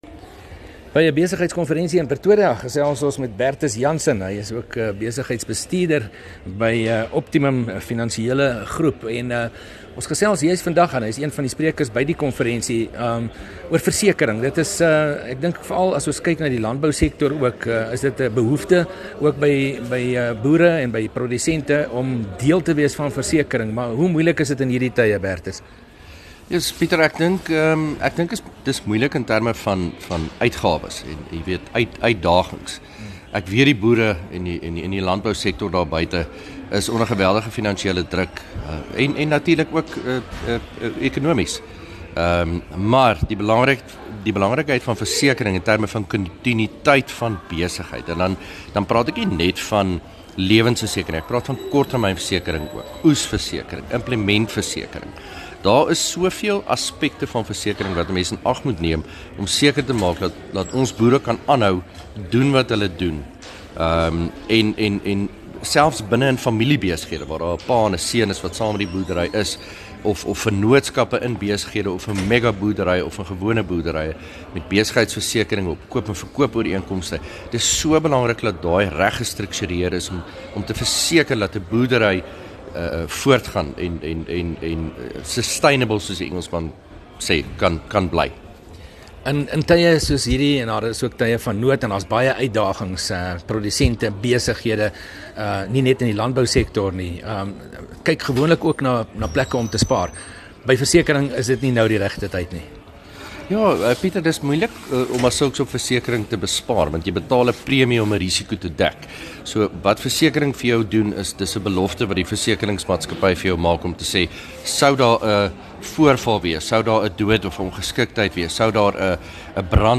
het by 'n onlangse konferensie met 'n kenner gesels oor landbouprodusente se versekering te midde van moeilike ekonomiese tye.